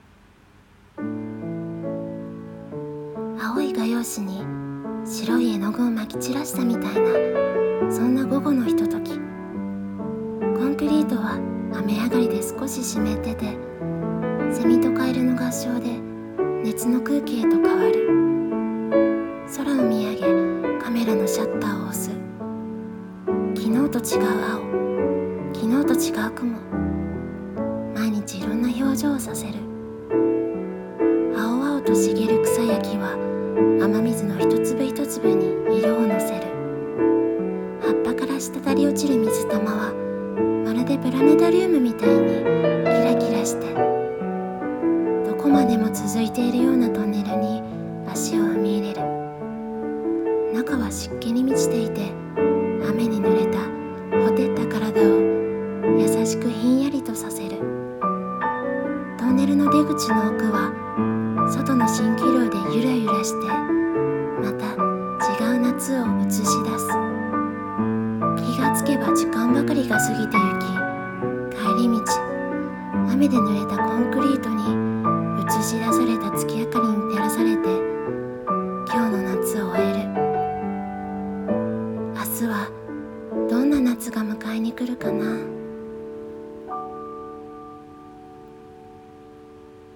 夏色、青模様。】1人声劇